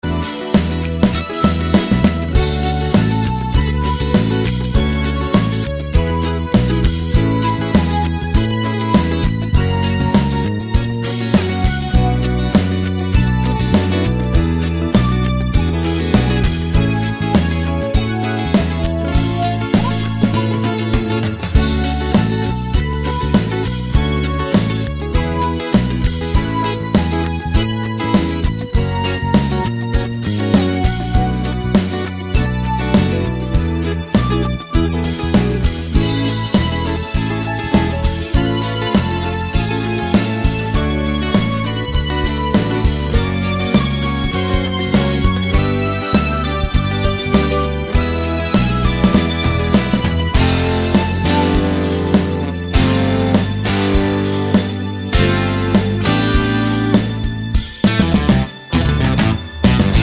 no Backing Vocals Pop (1970s) 3:52 Buy £1.50